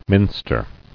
[min·ster]